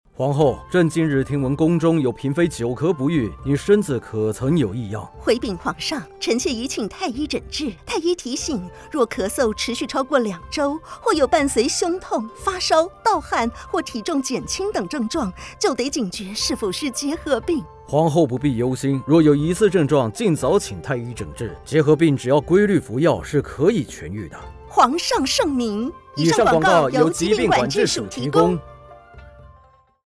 轉知~衛生福利部疾病管制署錄製之結核病及潛伏結核感染廣播衛教素材
結核病30秒廣播-宮廷劇篇_國語.mp3